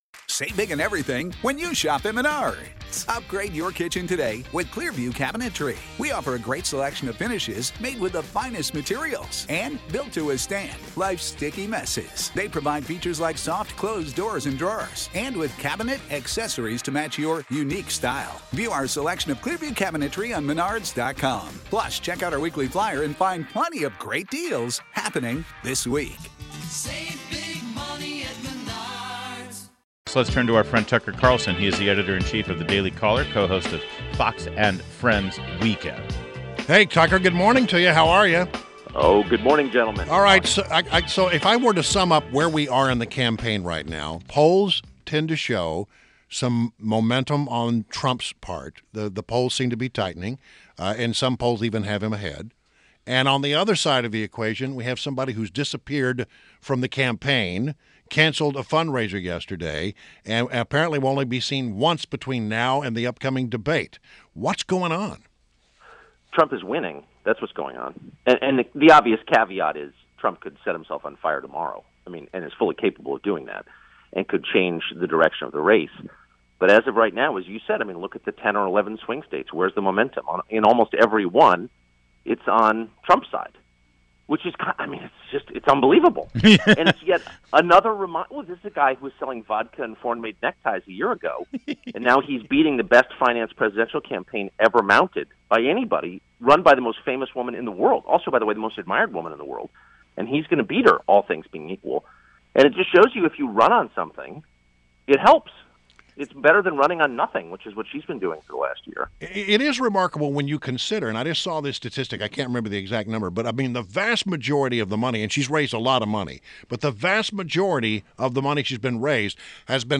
WMAL Interview - TUCKER CARLSON - 09.21.16
INTERVIEW -- TUCKER CARLSON - Editor-in-Chief of The Daily Caller and co-host of Fox and Friends Weekend